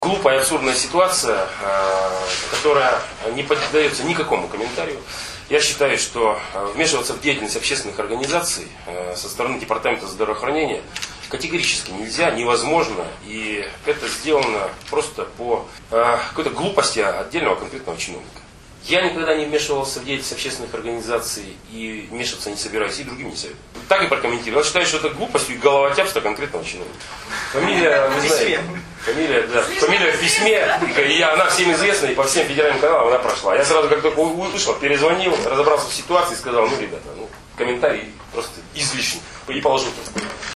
Ситуацию с международной федерацией «Общество Красного креста и Красного полумесяца» и региональным Департаментом здравоохранения губернатор Олег Кувшинников назвал головотяпством конкретного чиновника. Об этом он заявил во вторник, 10 апреля, в ходе пресс-конференции в ИА «СеверИнформ».
Олег Кувшинников комментирует ситуацию с "Красным крестом"